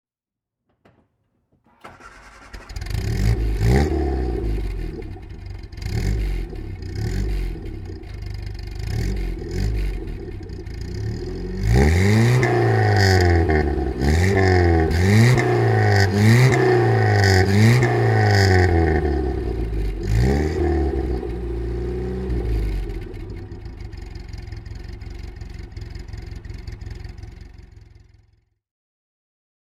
Engine sounds of Morris vehicles (random selection)